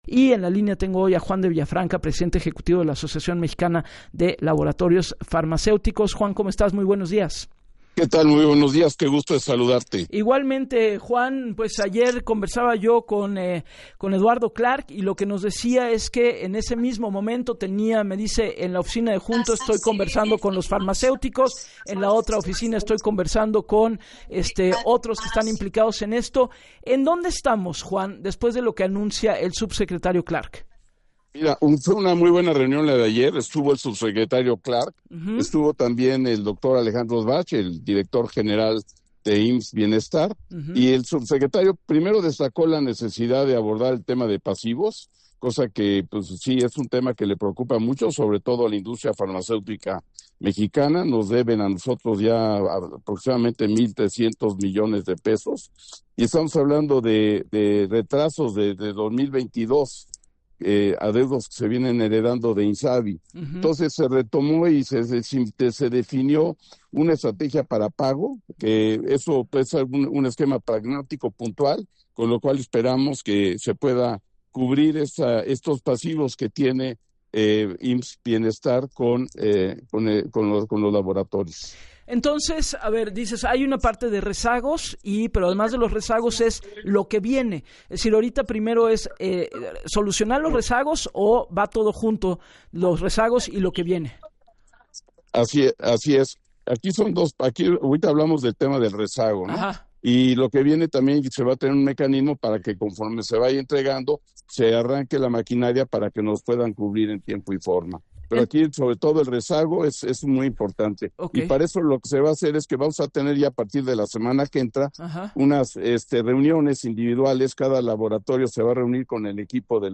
En entrevista para “Así las Cosas” con Gabriela Warkentin, puntualizó que será “a partir de la semana que entra que tendremos reuniones individuales con el secretario y subsecretario para conciliar adeudos y establecer el esquema de pagos”.